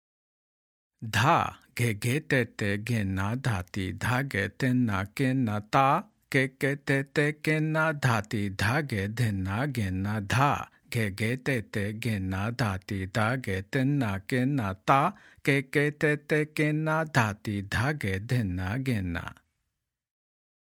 Spoken – Medium Speed